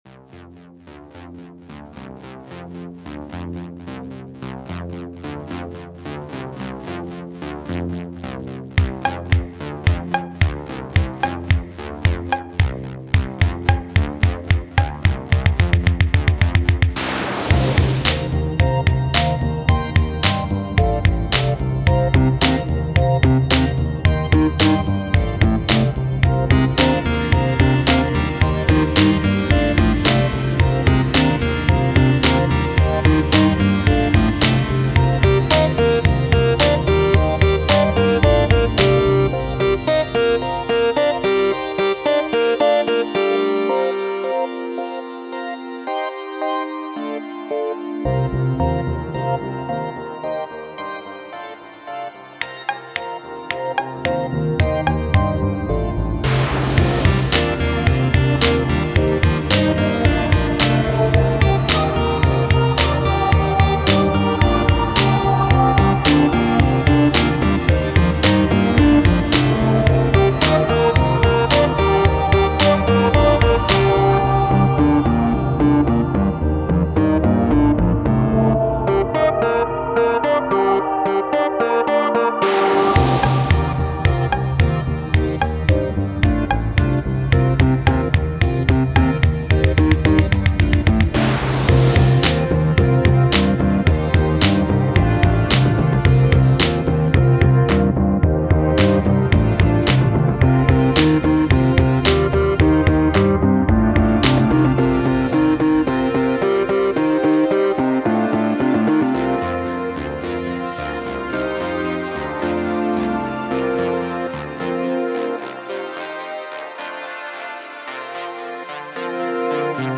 Doesn't loop, but has a smooth transition.